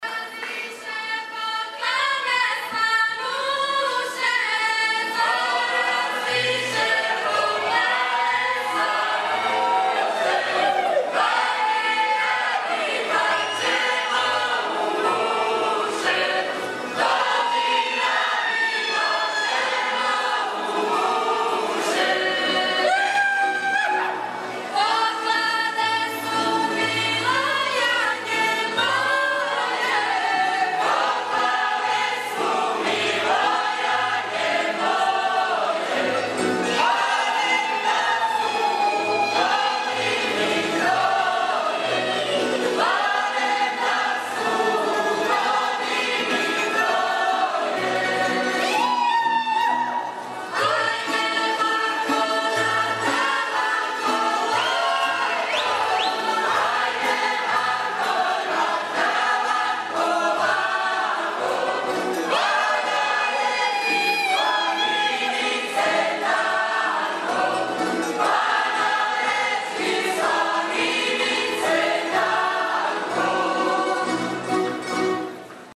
Christmas Show Songs – 2017
7th and 8th Grades